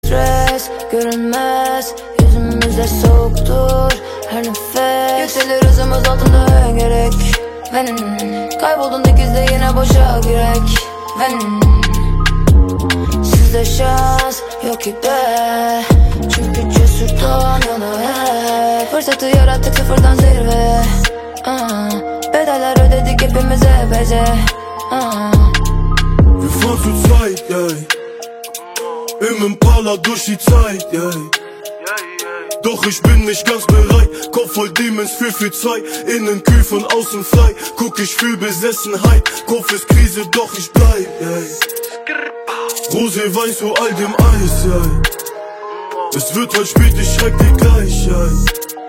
Rap/Hip Hop